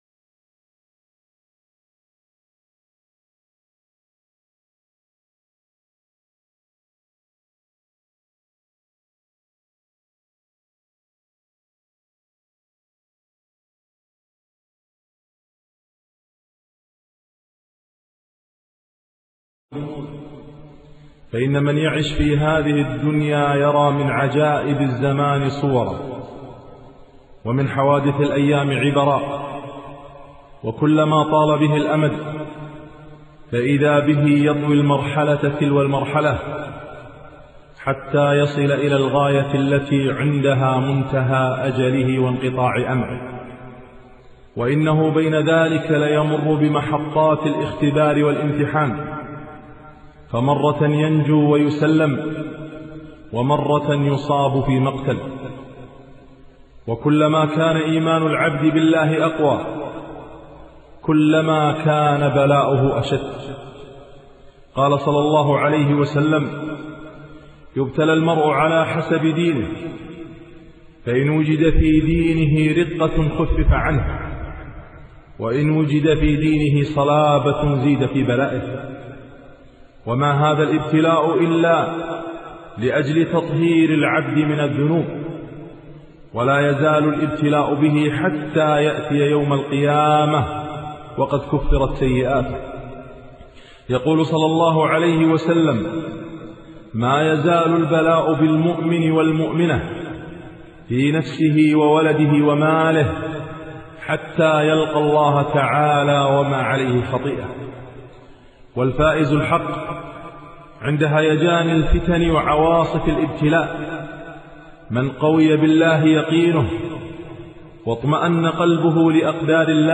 خطبة - فقد الأحبة